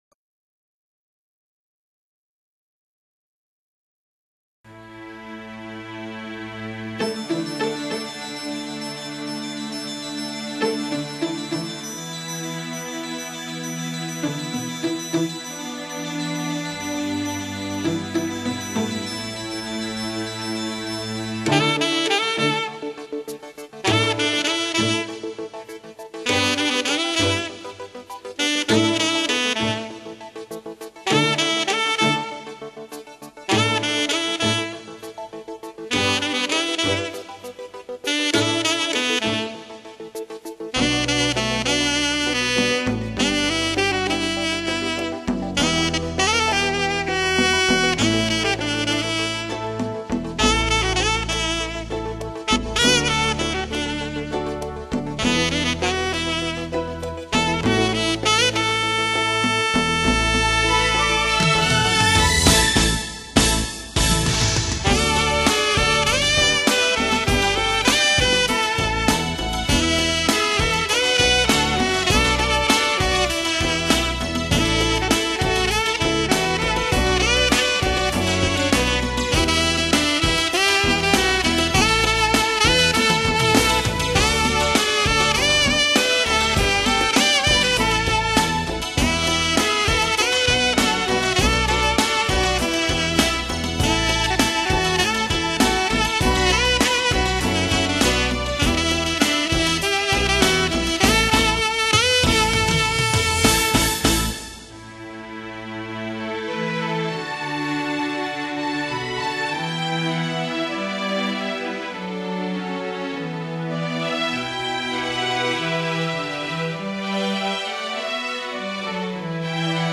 萨克斯风的独特磁场所舒放的温柔优雅旋律,就似娇羞的少女流露的无尽轻柔,让您打从心坎里被这情韵撩拔。